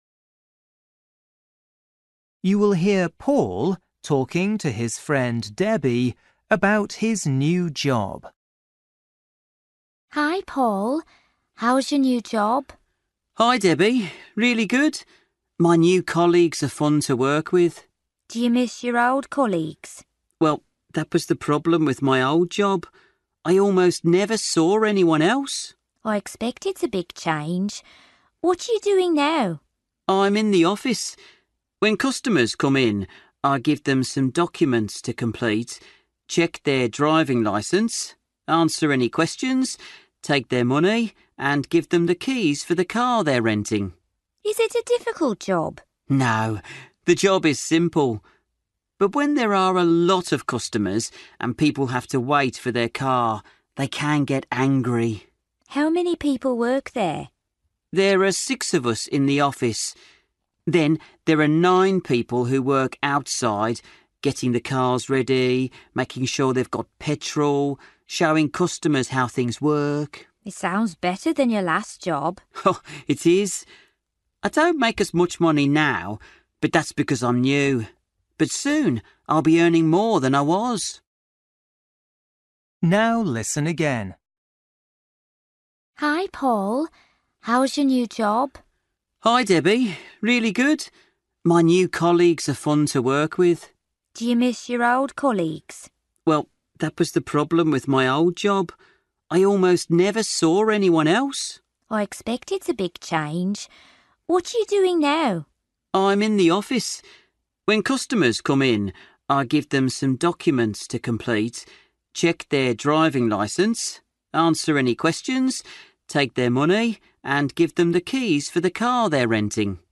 Bài tập trắc nghiệm luyện nghe tiếng Anh trình độ sơ trung cấp – Nghe một cuộc trò chuyện dài phần 13
You will hear Paul talking to his friend, Debbie, about his new job.